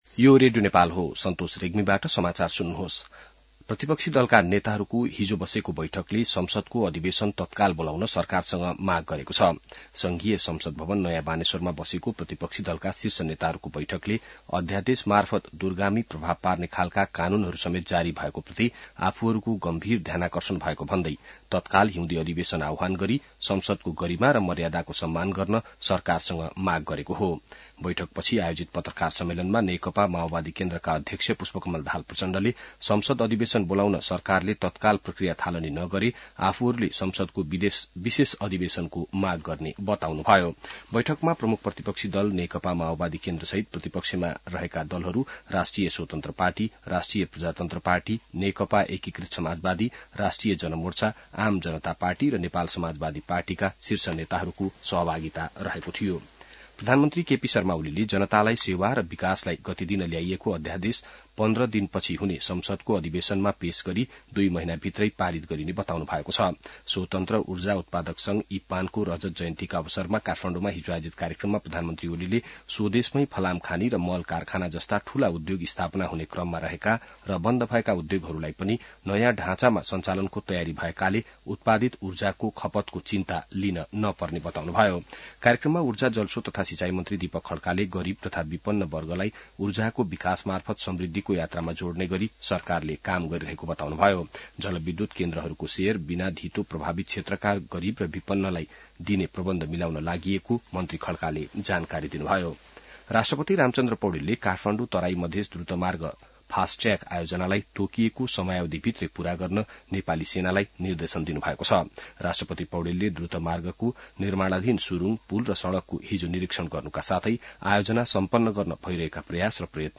बिहान ६ बजेको नेपाली समाचार : ६ माघ , २०८१